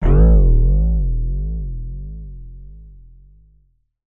comedy_spring_twang_or_pluck_006